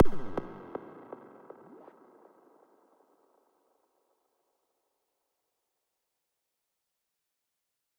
鼓循环第1章的混响
标签： 循环 120-BPM 敲击
声道立体声